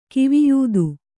♪ kiviyūdu